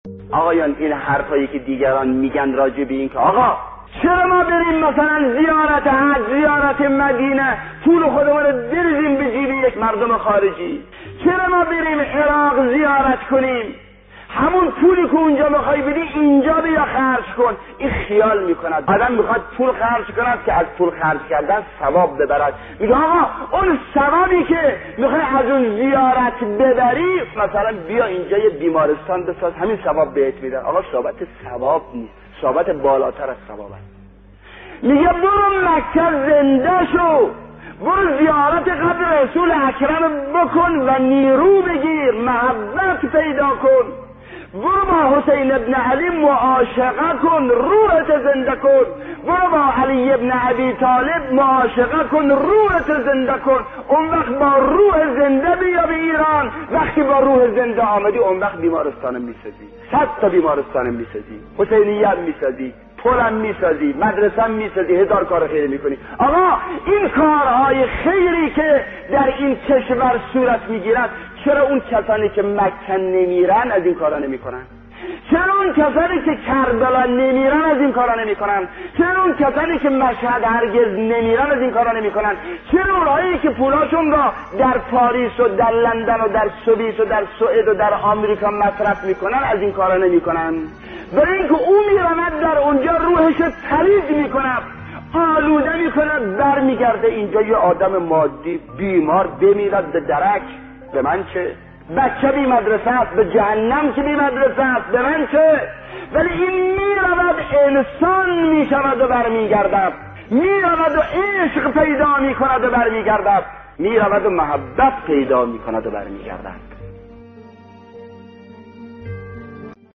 به گزارش خبرگزاری حوزه، استاد شهید مطهری در یکی از سخنرانی های خود به سؤالی پیرامون موضوع «آیا خرج زیارت بهتر است یا مدرسه ساختن؟» پرداختند که تقدیم شما فرهیختگان می شود.